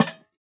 Minecraft Version Minecraft Version snapshot Latest Release | Latest Snapshot snapshot / assets / minecraft / sounds / block / lantern / place2.ogg Compare With Compare With Latest Release | Latest Snapshot